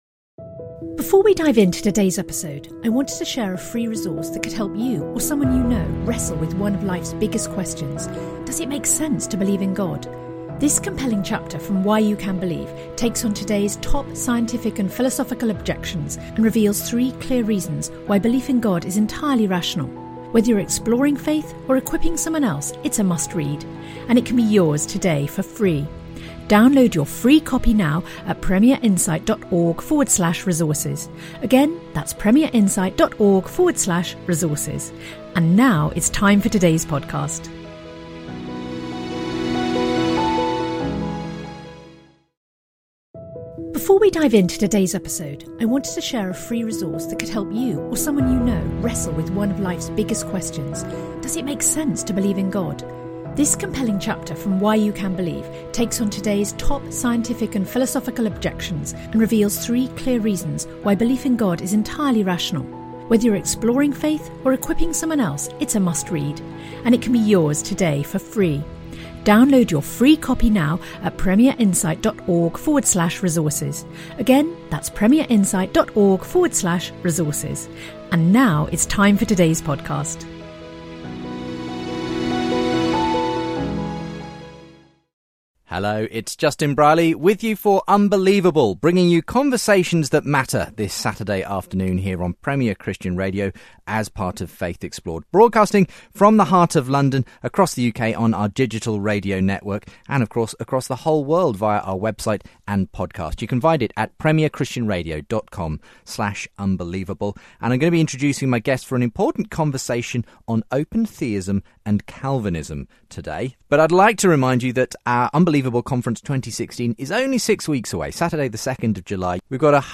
They debate the issues.